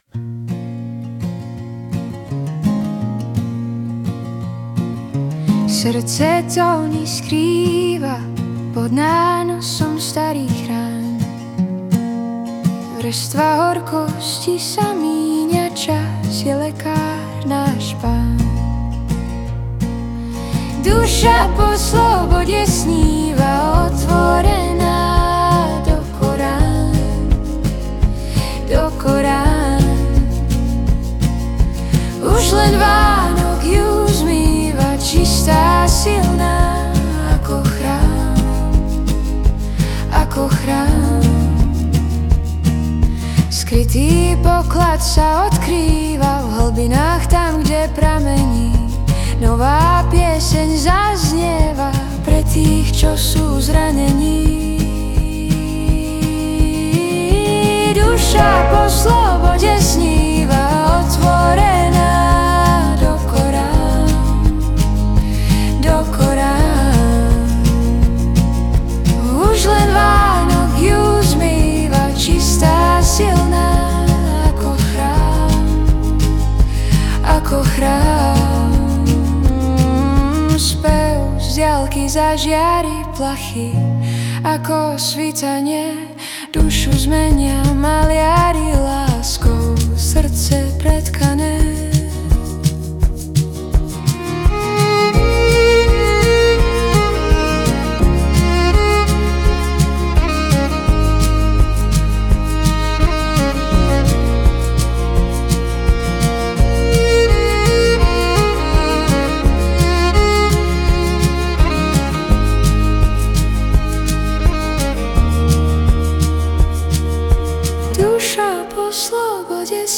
Hudba a spev AI
Balady, romance » Romantické